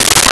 Water Drip 02
Water Drip 02.wav